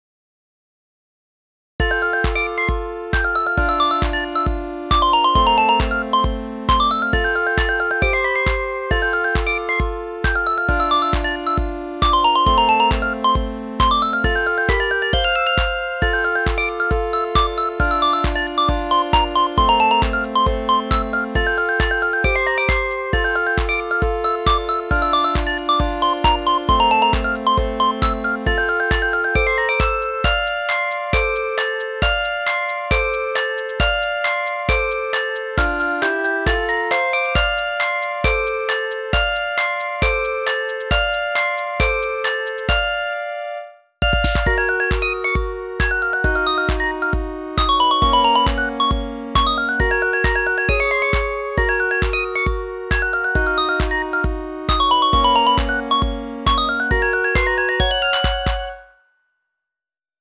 電子音中心の可愛らしい曲ですー